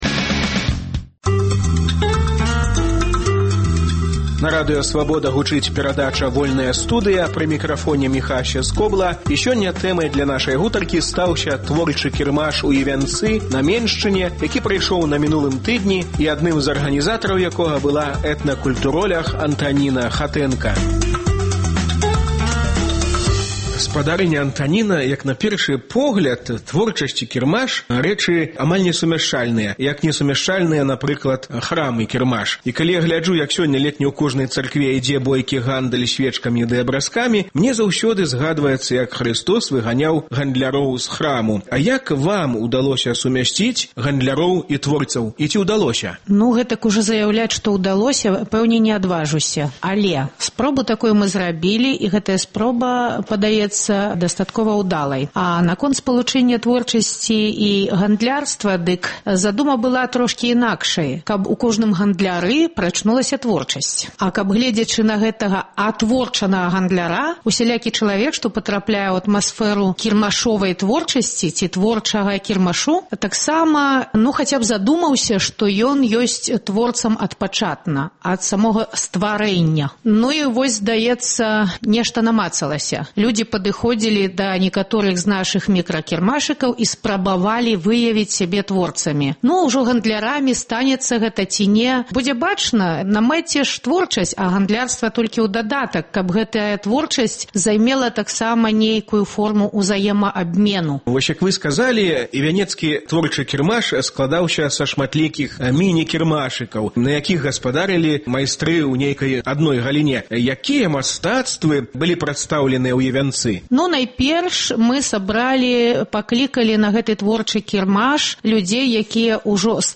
Як разварушыць народ? Гутарка